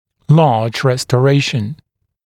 [lɑːʤ ˌrestə’reɪʃn][ла:дж ˌрэстэ’рэйшн]большая реставрация (зуба)